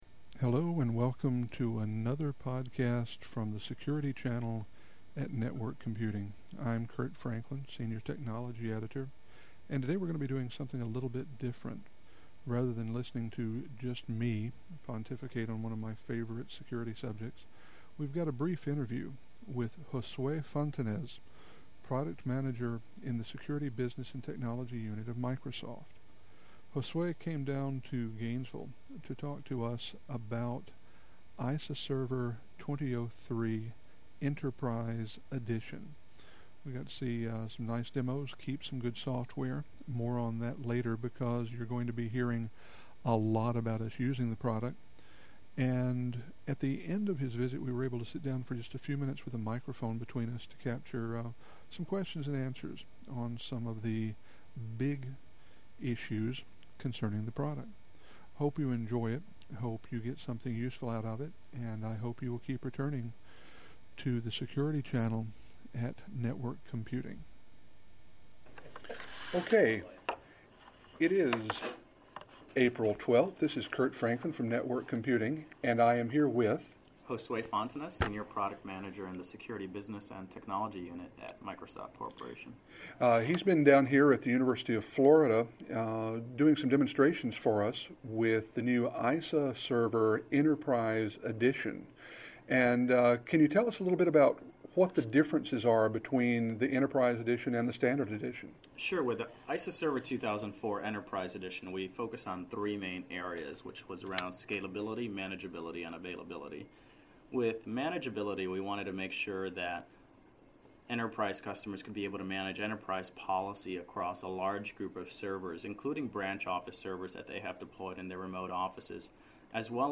In addition to the demo, we were able to talk for a while, and part of the conversation makes up today's podcast, which you can find